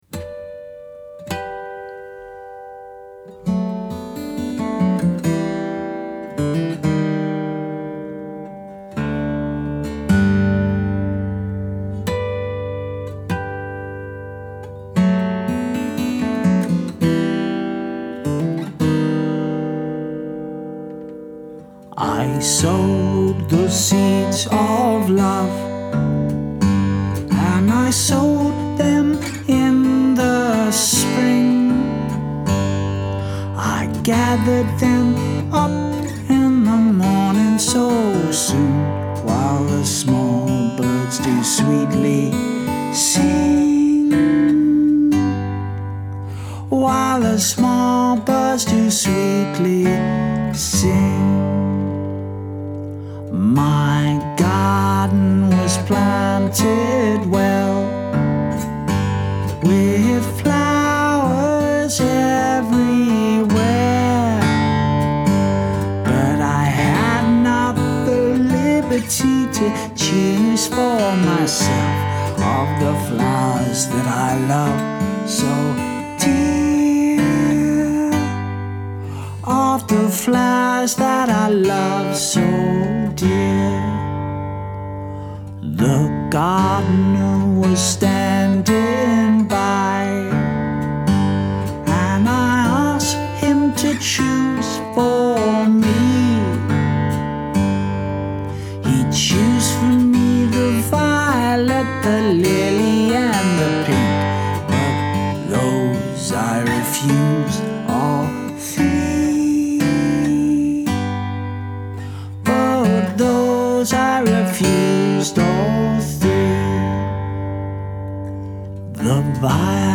I released an album of songs from the traditional British folk repertoire in December 2018 & I’m really proud of it.